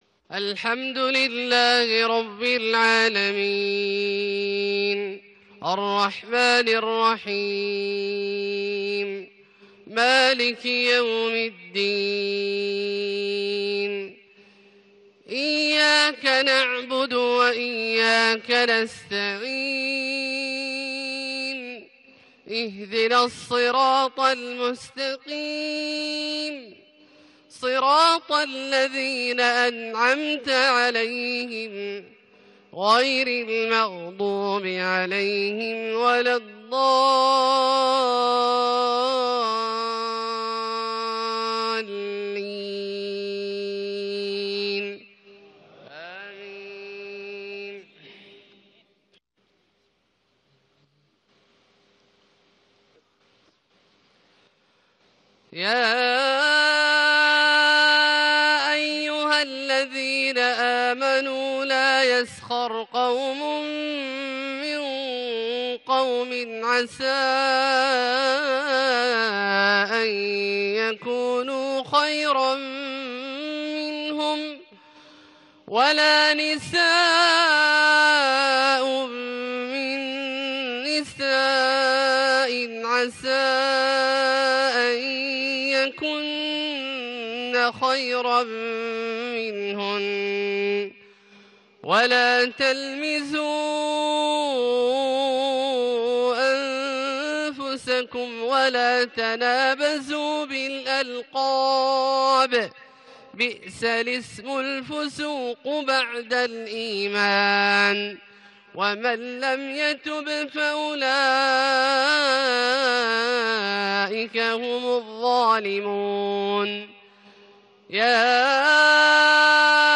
صلاة العشاء 9-6-1438 من سورة الحجرات {11-18} > ١٤٣٨ هـ > الفروض - تلاوات عبدالله الجهني